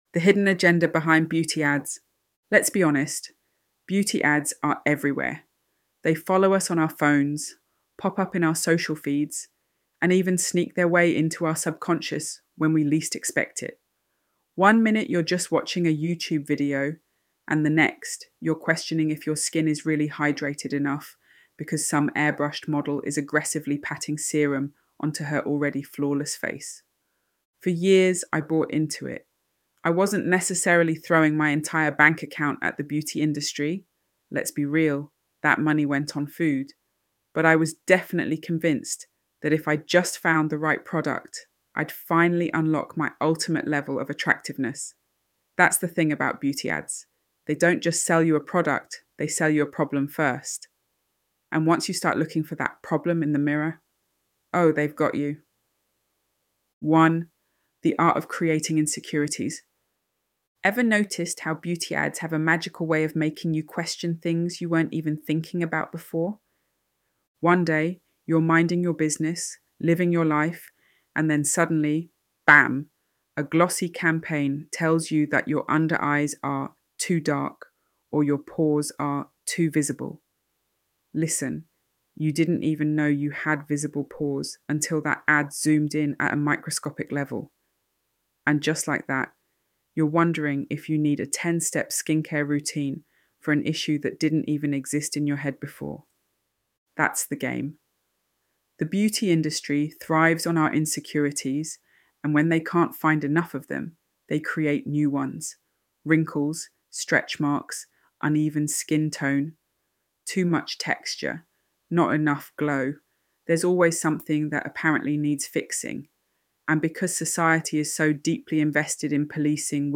ElevenLabs_The_Hidden_Agenda_Behind_Beauty_Ads.mp3